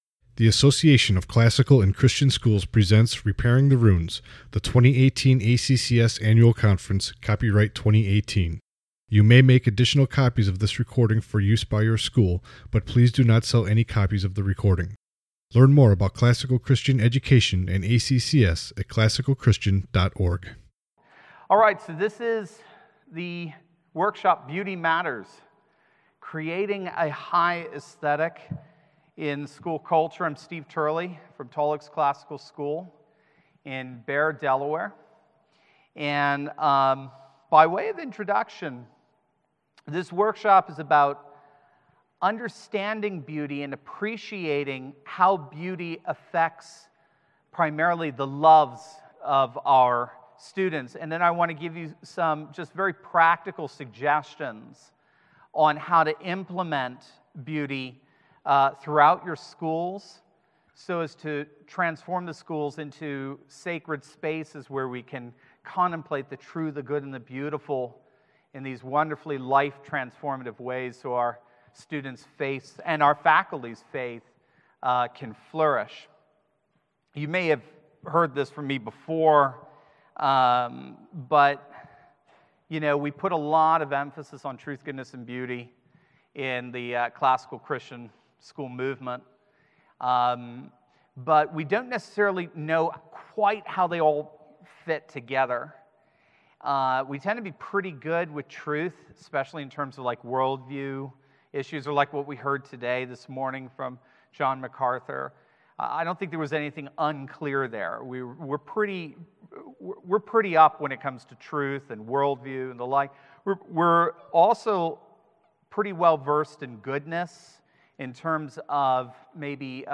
Jan 15, 2019 | All Grade Levels, Conference Talks, Foundations Talk, General Classroom, Library, Media_Audio | 0 comments
Additional Materials The Association of Classical & Christian Schools presents Repairing the Ruins, the ACCS annual conference, copyright ACCS.